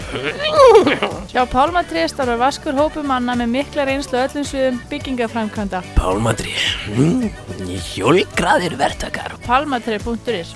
��essa augl�singu h�r a� ne�an m� v�st heyra � vi�t�kjum sunnlendinga um �essar mundir.